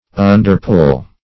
Search Result for " underpull" : The Collaborative International Dictionary of English v.0.48: Underpull \Un`der*pull"\, v. i. To exert one's influence secretly.